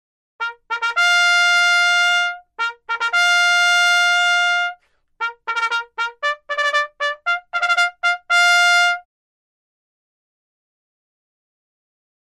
Category: Sound FX   Right: Personal
Tags: nature sounds raven ocean wind thunder/lightening